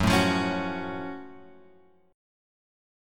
F#mM7 chord {2 0 3 x 2 2} chord